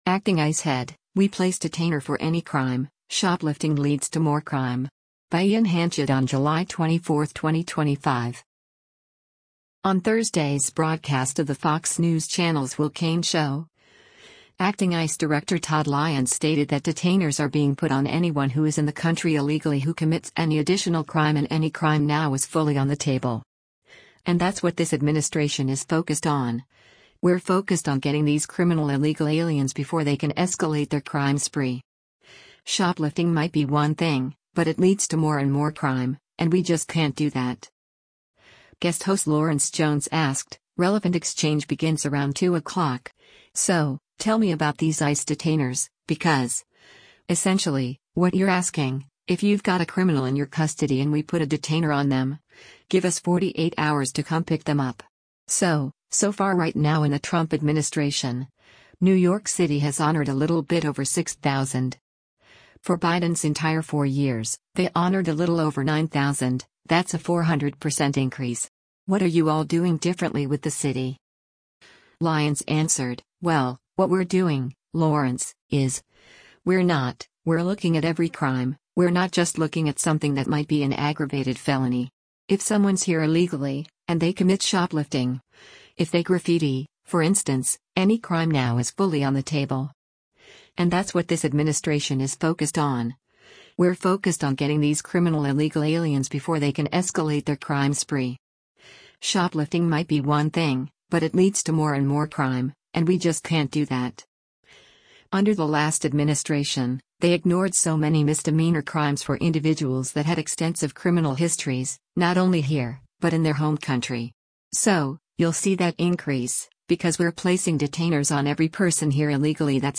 On Thursday’s broadcast of the Fox News Channel’s “Will Cain Show,” acting ICE Director Todd Lyons stated that detainers are being put on anyone who is in the country illegally who commits any additional crime and “any crime now is fully on the table. And that’s what this administration is focused on, we’re focused on getting these criminal illegal aliens before they can escalate their crime spree. Shoplifting might be one thing, but it leads to more and more crime, and we just can’t do that.”